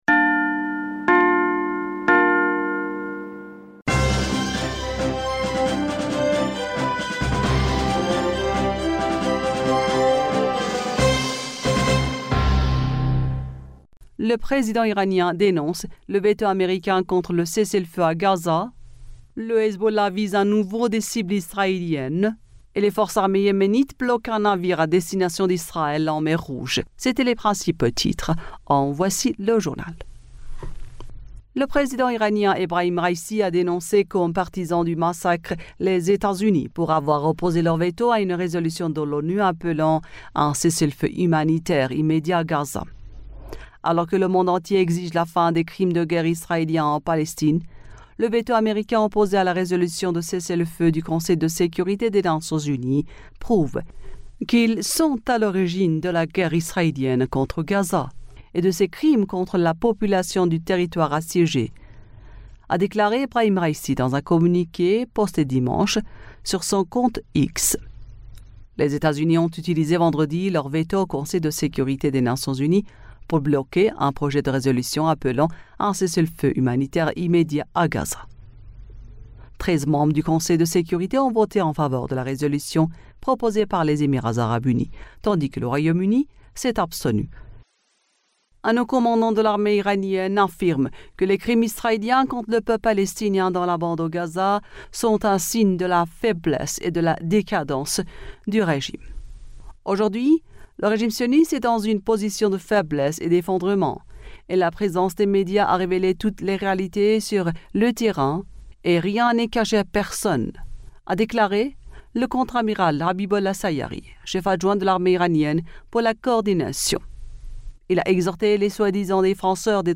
Bulletin d'information du 11 Decembre 2023